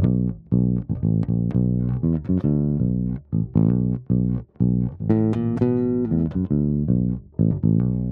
12 Bass PT3.wav